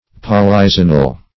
Search Result for " polyzonal" : The Collaborative International Dictionary of English v.0.48: Polyzonal \Pol`y*zon"al\, a. [Poly- + zonal.] Consisting of many zones or rings.